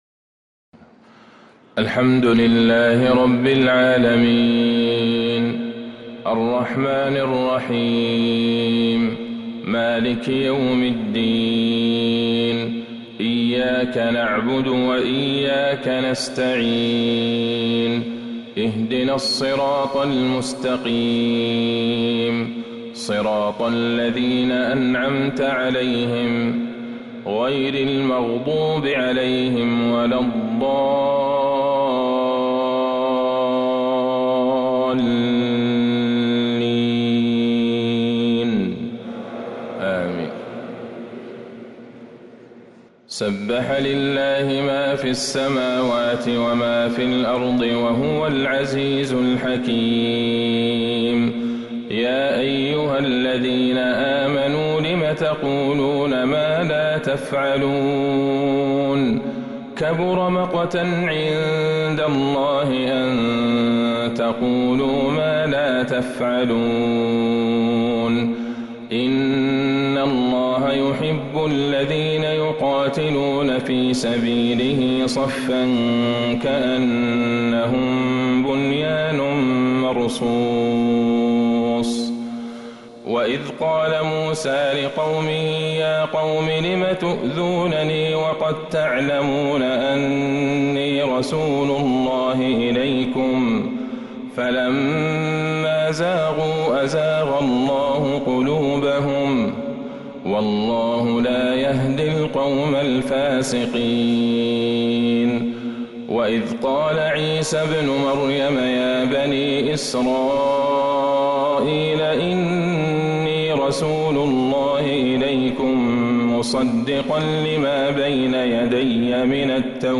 فجر الإثنين 9-3-1447هـ | سورة الصف كاملة | Fajr prayer from surah as-Saff 1-9-2025 > 1447 🕌 > الفروض - تلاوات الحرمين